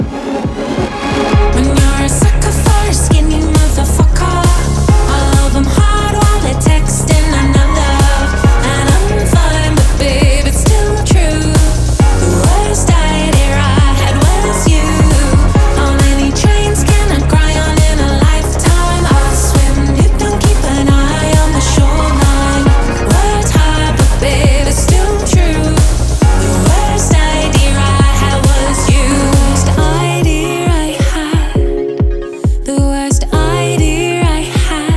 Скачать припев
Singer Songwriter